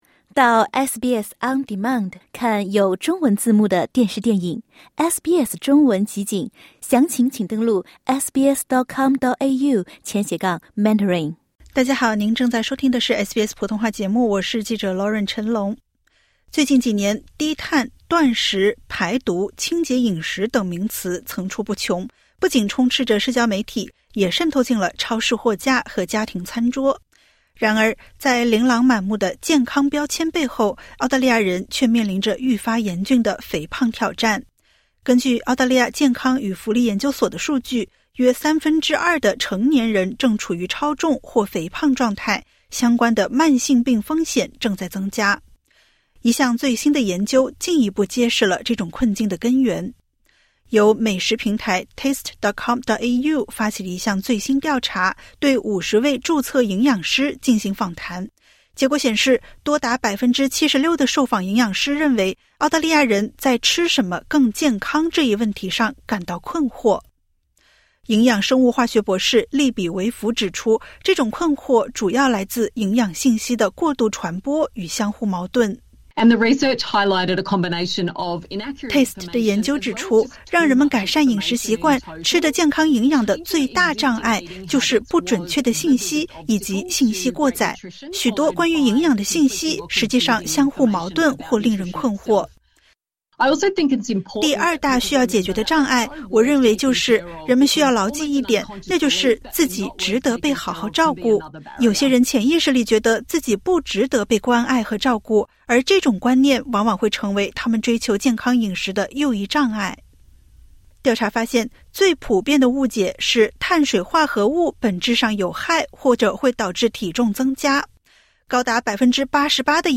最新一项针对注册营养师的调查显示，多数专家认为，公众对碳水化合物存在根深蒂固的误解，而这正是阻碍健康饮食习惯形成的重要原因。点击 ▶ 收听完整报道。